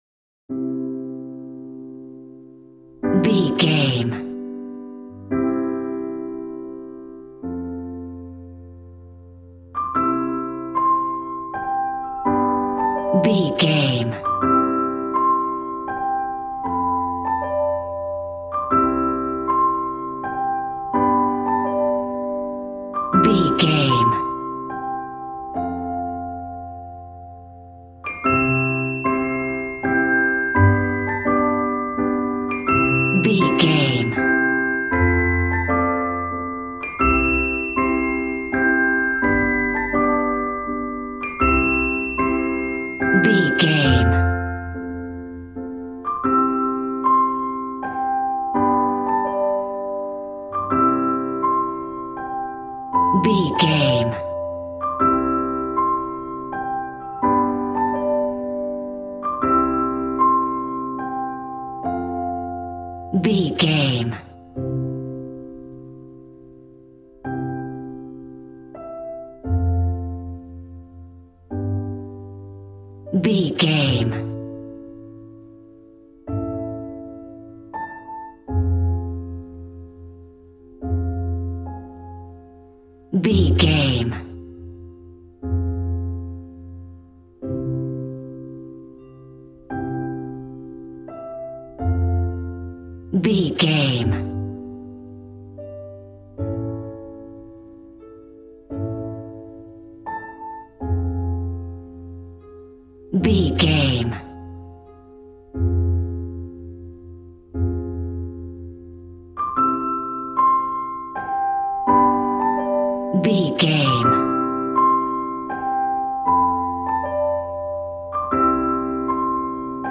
Regal and romantic, a classy piece of classical music.
Ionian/Major
regal
strings
violin